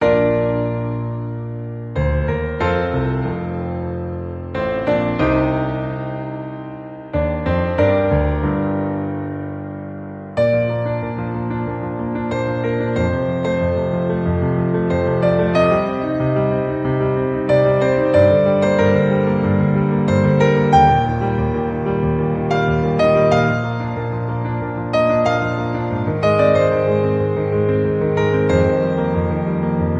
• Key: Bb major
• Instruments: Piano solo
• Genre: Pop